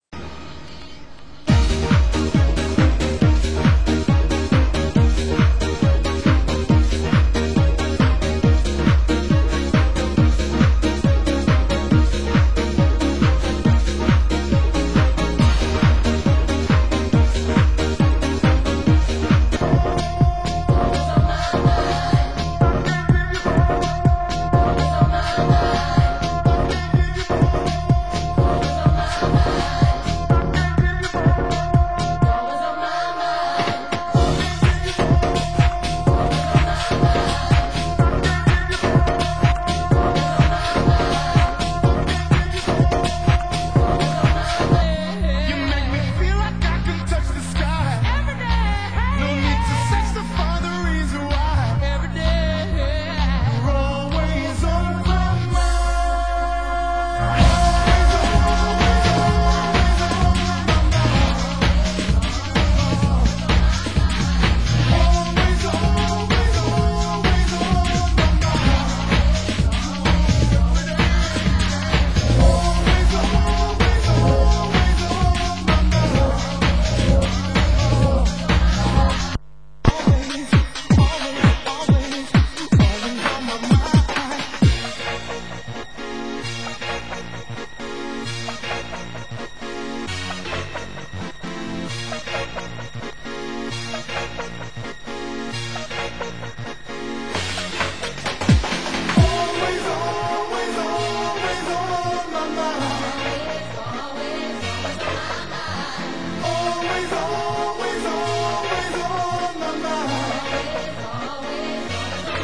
Genre UK Garage